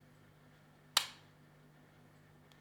Light switch on 2
light switch on 2.WAV